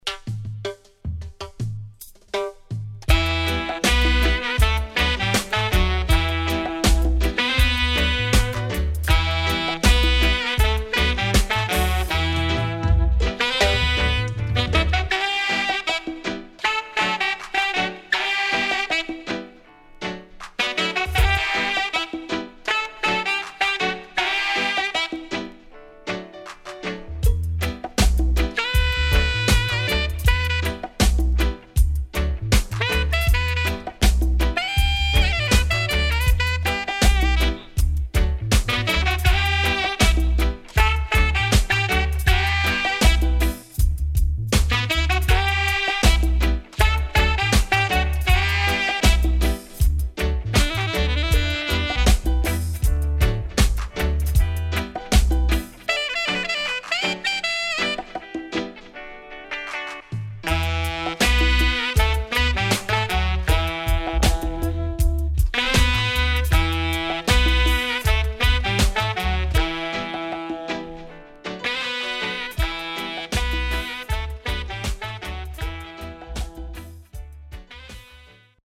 HOME > REISSUE [DANCEHALL]